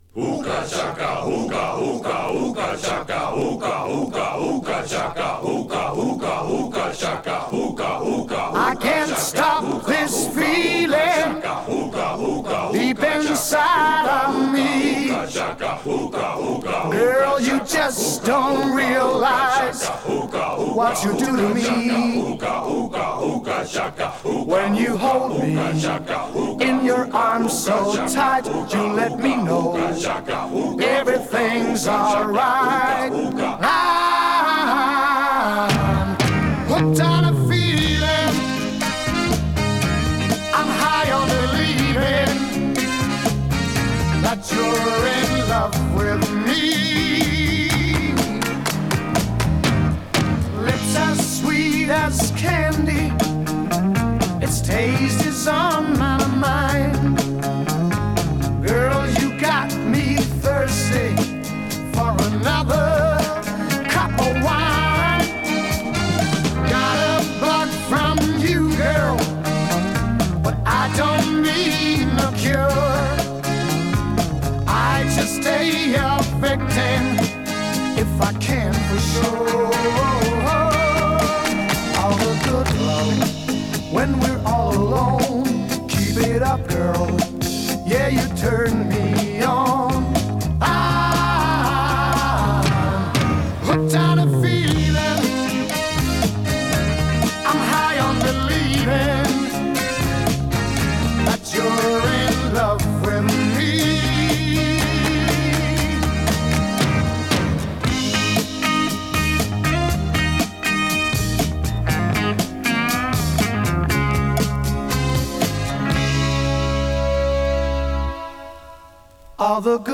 We are going to listen to some music that came up a lot on our road trips in the early 2000’s and talk about the memories that they invoke, comparing our experiences.